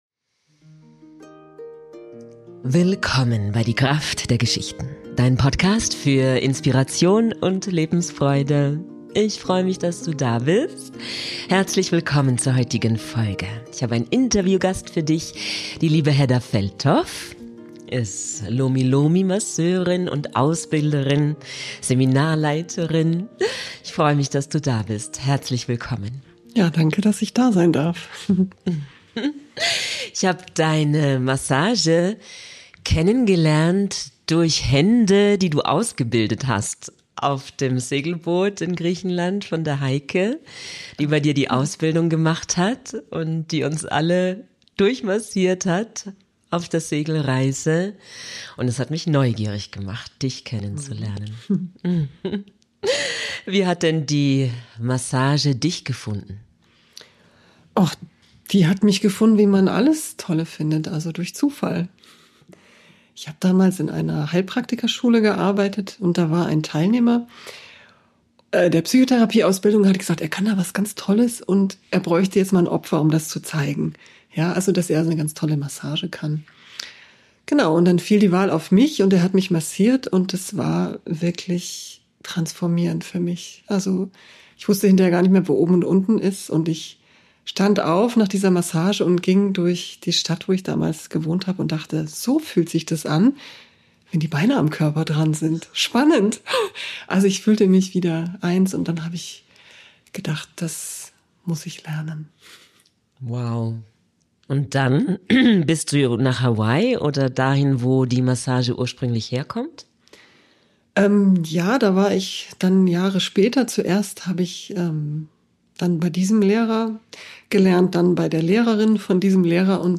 Mach´s Dir gemütlich und lausch unserem Gespräch.